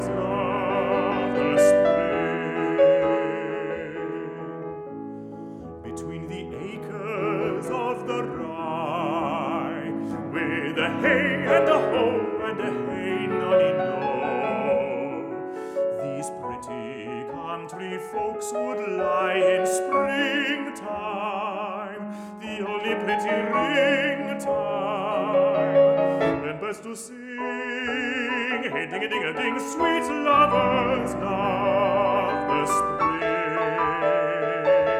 Classical Art Song
Жанр: Классика